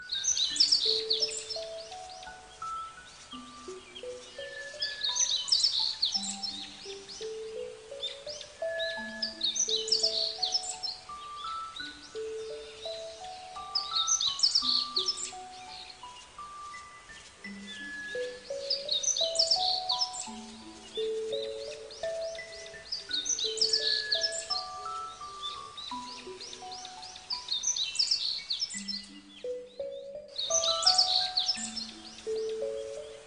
燕雀河水里洗澡叫声